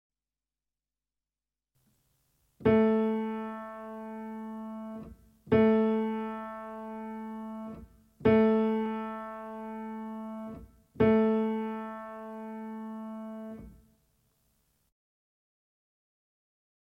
57 Tuning note - A-string (Cello)